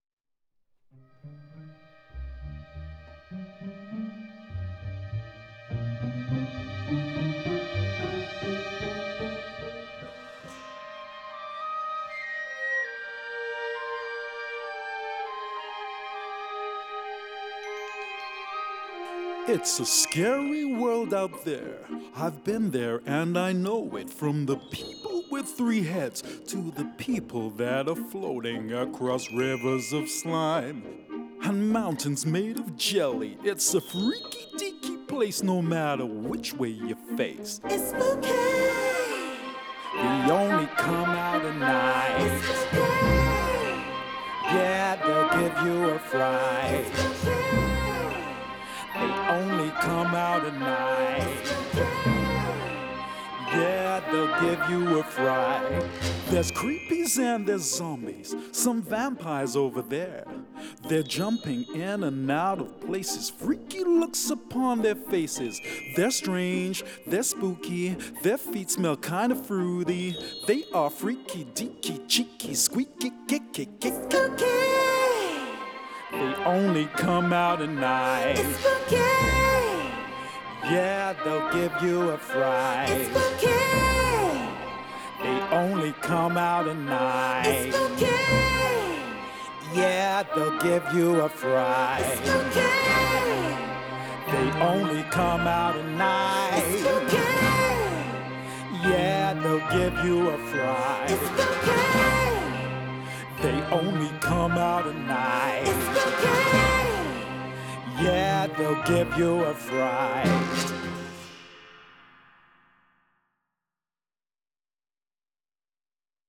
recorded in a performance setting